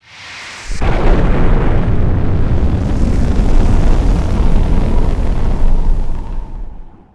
Real nuke sound.
agreed - it needs more base. At the moment it sounds abit flat Sad